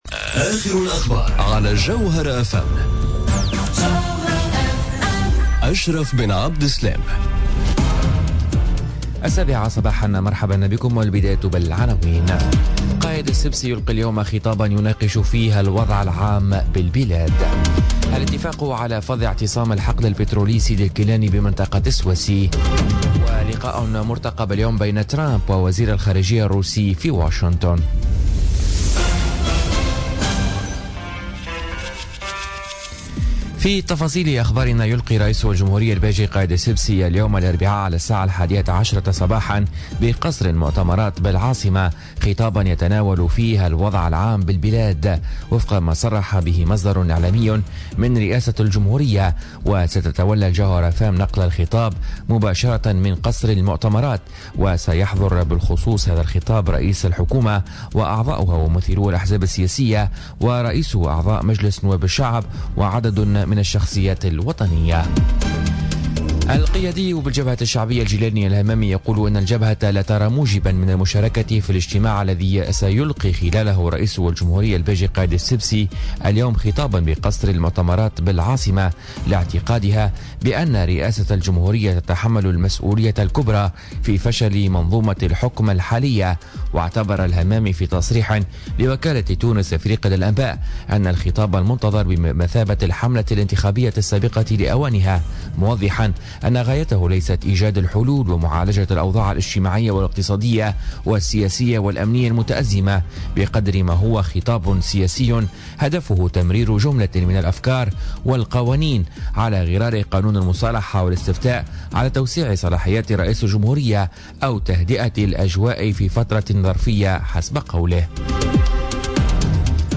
نشرة أخبار السابعة صباحا ليوم الإربعاء 10 ماي 2017